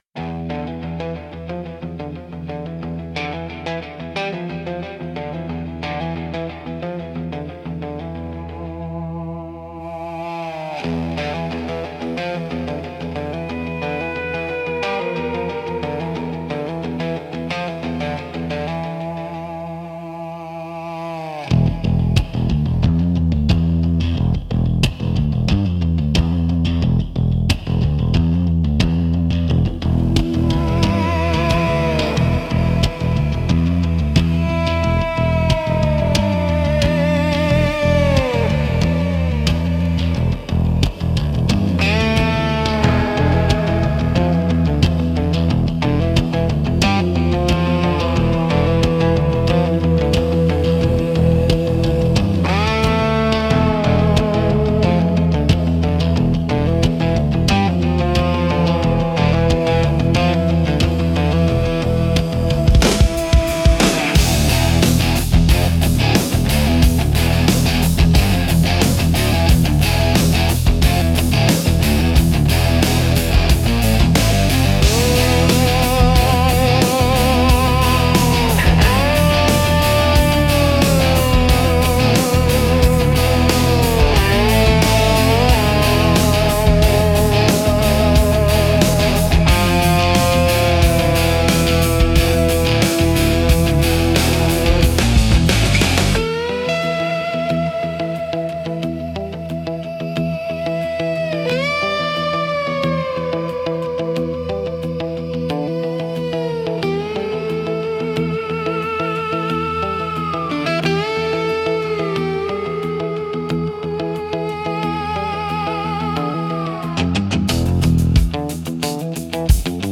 Instrumental - Tremolo for a Lost Signal 5.14